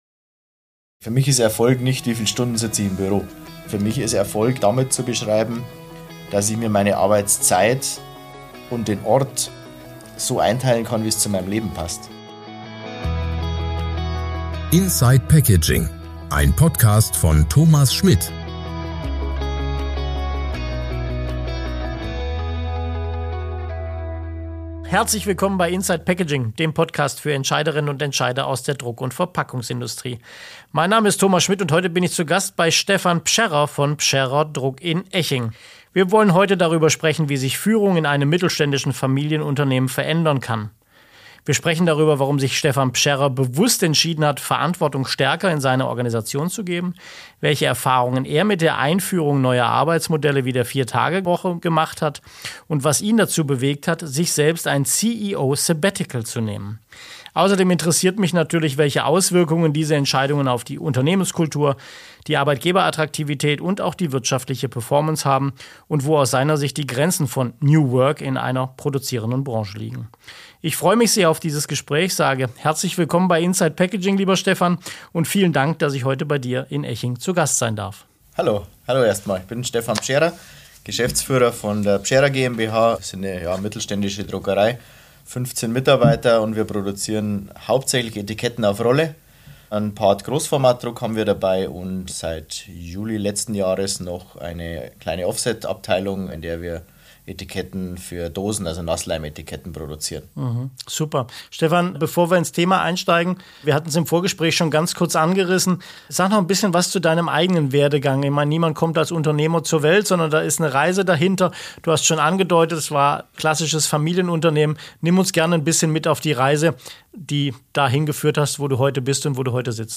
Ein inspirierendes Gespräch für alle, die Arbeitszeit, Führung und Erfolg neu denken wollen.